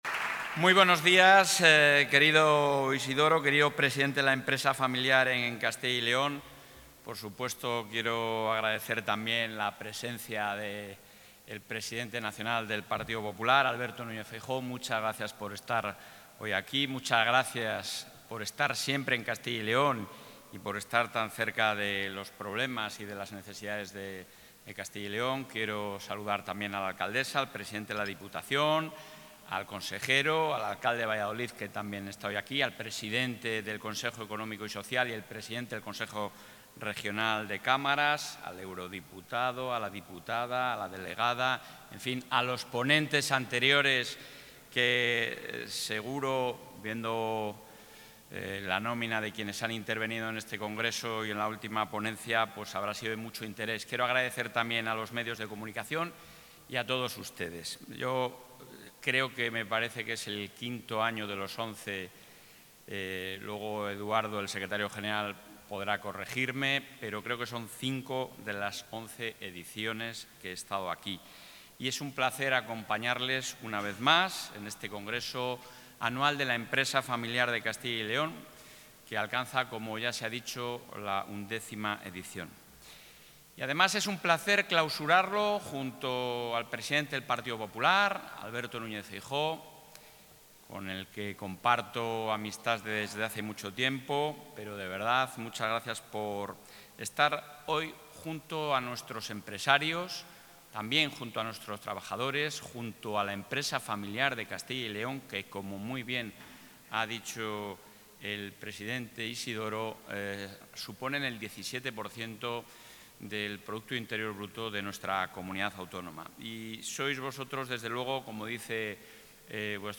El presidente de la Junta de Castilla y León, Alfonso Fernández Mañueco, ha participado este viernes en el XI Congreso de...
Intervención del presidente de la Junta.